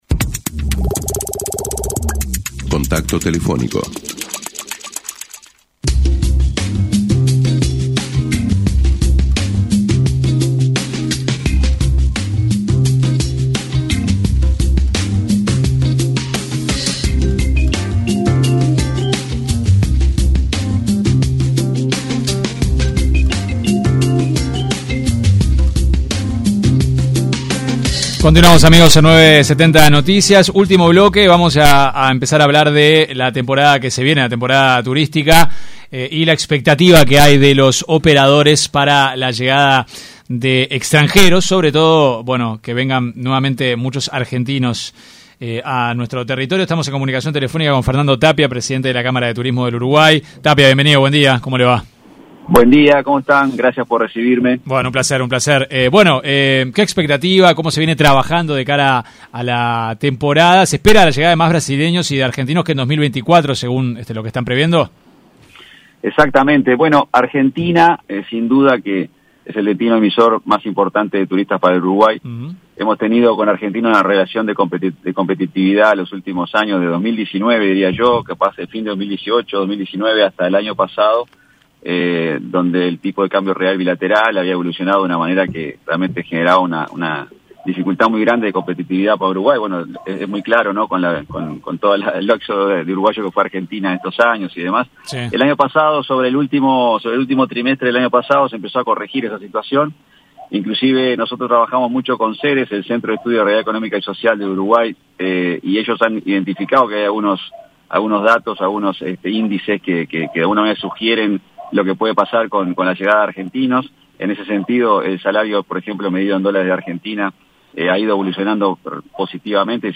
Así lo expresó en una entrevista con 970 Noticias